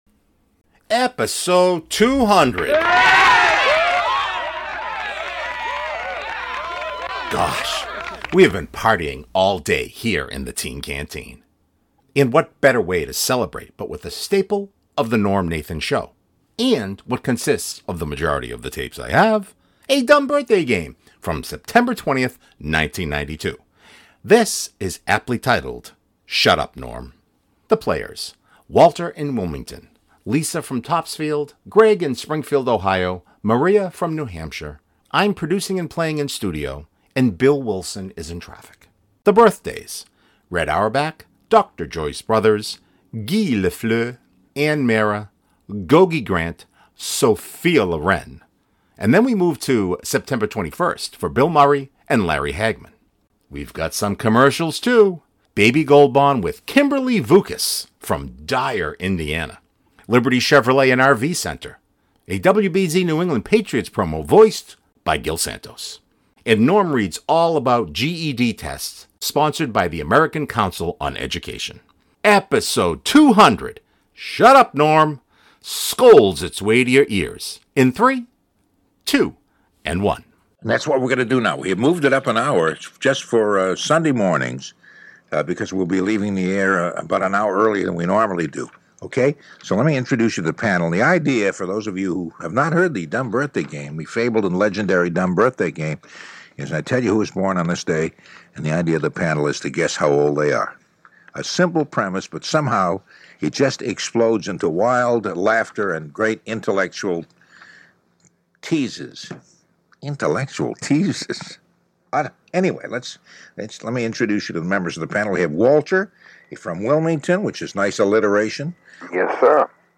Episode 200! Gosh, we have been partying all day here in the Teen Canteen!